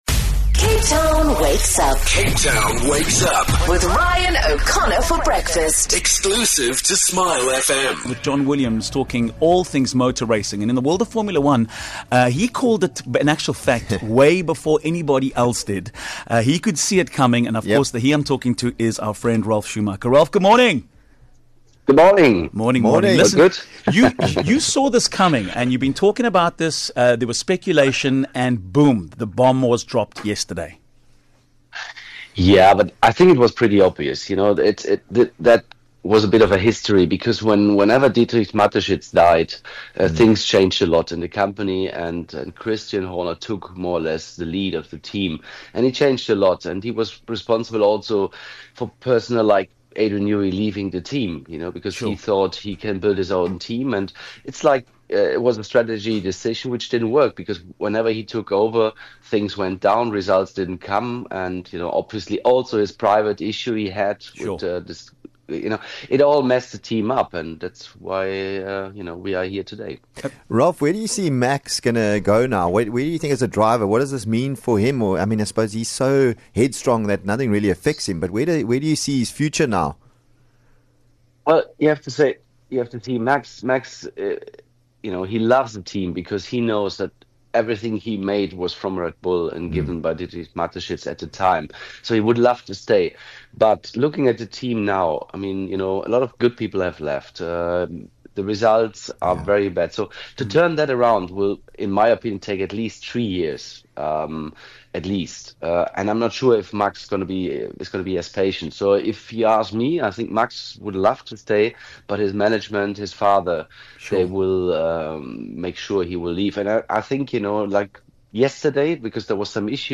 With the news of Christian Horner leaving Red Bull, we called up F1 legend Ralf Schumacher to weigh in.